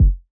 003_Kick_2_RedLine.wav